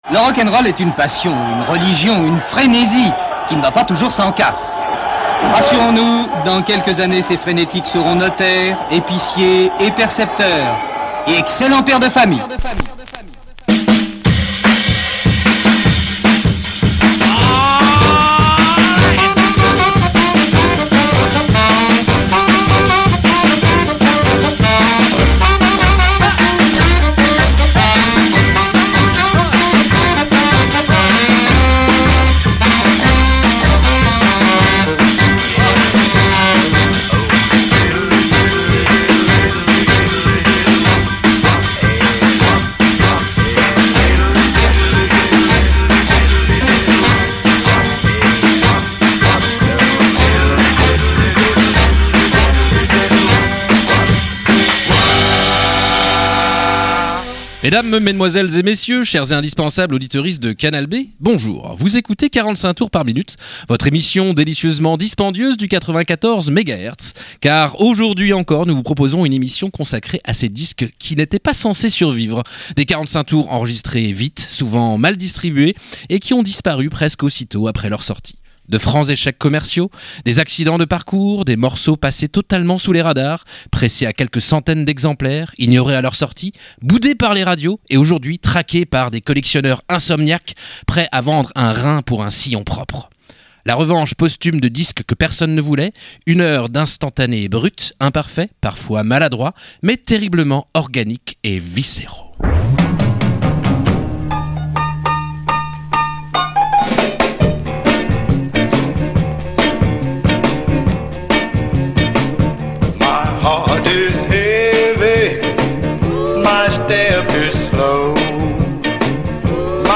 ⚠ Attention, cet épisode contient beaucoup plus de garage que d'habitude ⚠